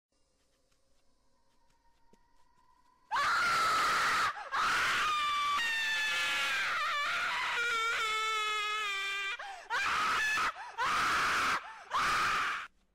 Tinky_winky_scream.mp3